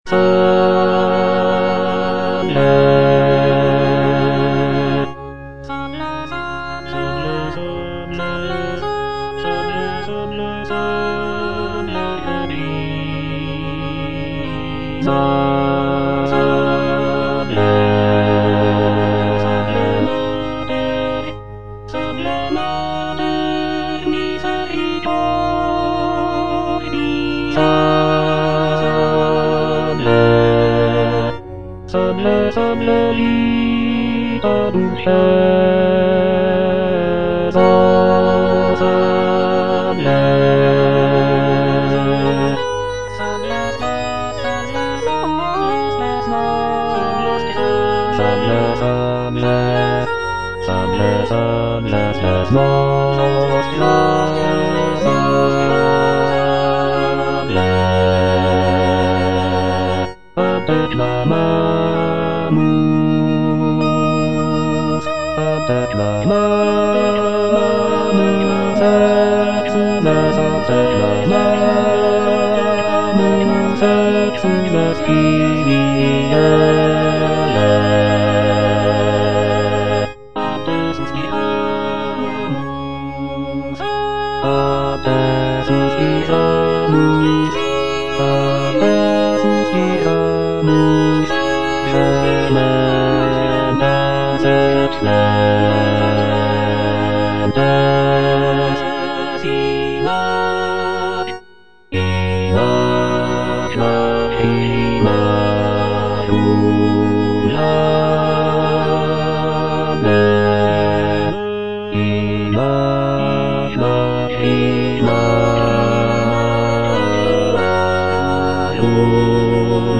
Choralplayer playing Salve, Regina (A = 415 Hz) by G.F. Sances based on the edition From
G.F. SANCES - SALVE, REGINA (A = 415 Hz) Bass (Emphasised voice and other voices) Ads stop: auto-stop
"Salve, Regina (A = 415 Hz)" is a sacred choral work composed by Giovanni Felice Sances in the 17th century.
The work features rich harmonies, expressive melodies, and intricate vocal lines, showcasing Sances' skill as a composer of sacred music.